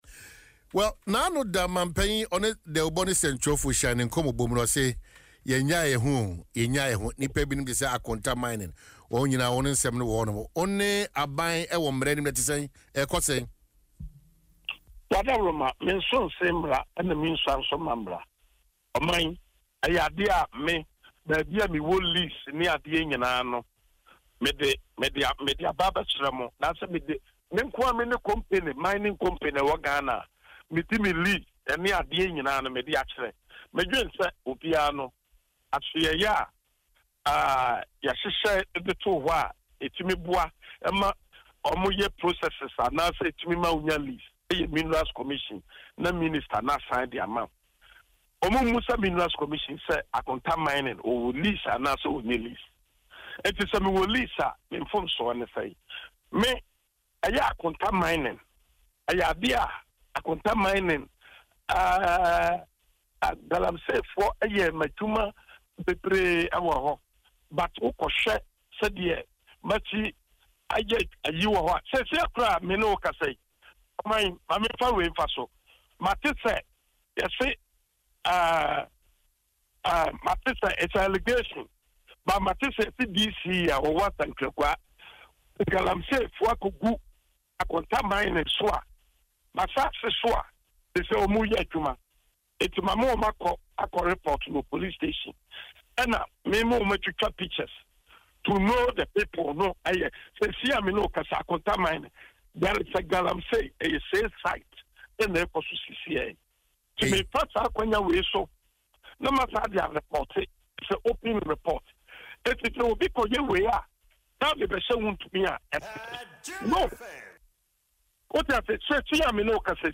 Speaking in an interview on Adom FM’s Dwaso Nsem